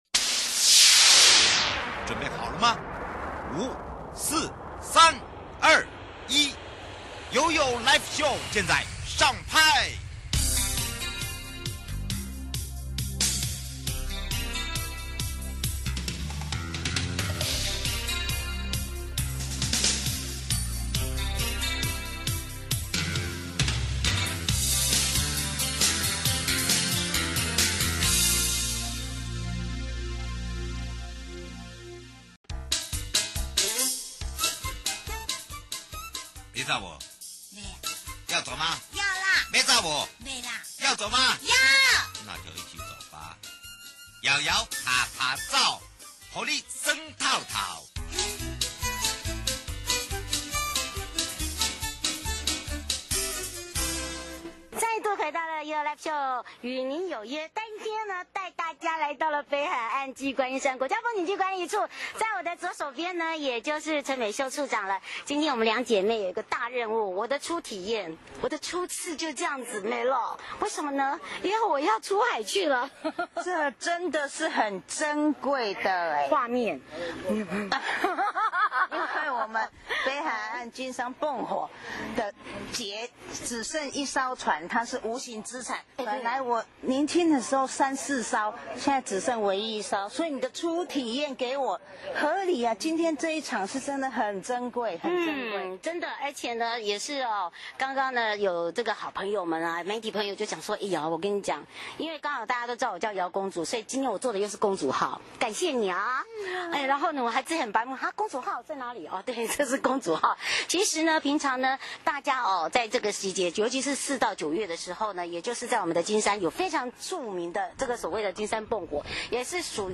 今年秋天澎湖你來了嗎 ?秋天來澎湖~觀光護照大加碼!食宿遊購行~抱你玩不停!!還有還有!!驚喜彩蛋喔喔! 受訪者： 1. 北觀管理處陳美秀處長 2. 澎湖管理處洪志光副處長